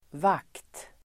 Uttal: [vak:t]